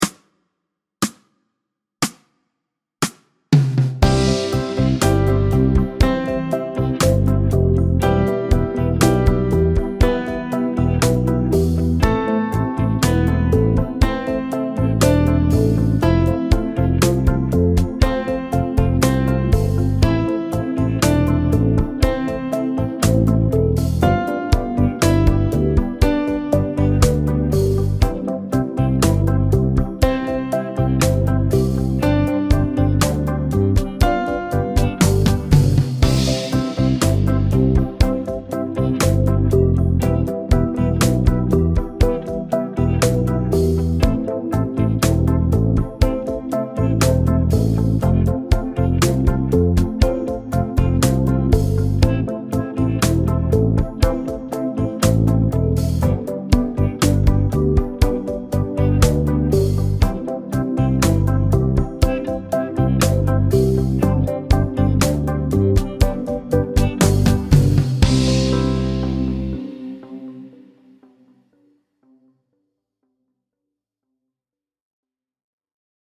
Slow C instr (demo)